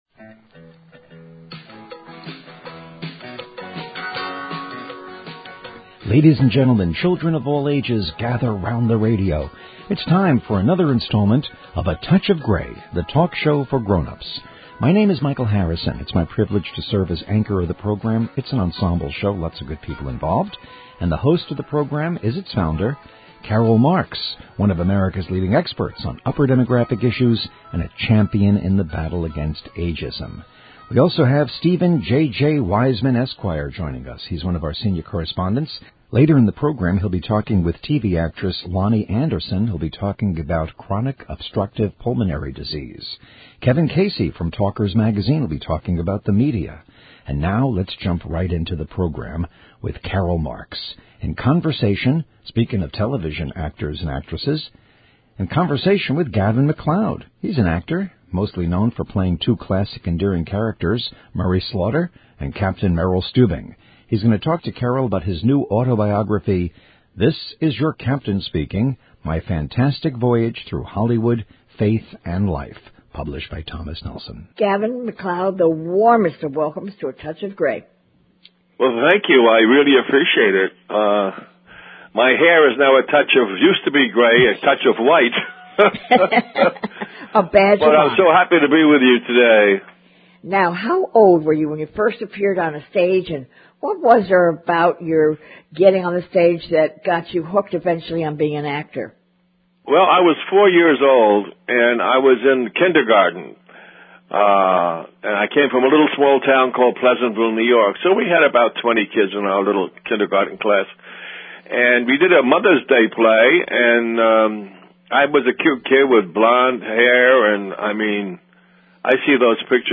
In this part of the interview, Gavin talks about what it was like to dance with Ginger Rogers, the success of The Love Boat and why he wrote the book.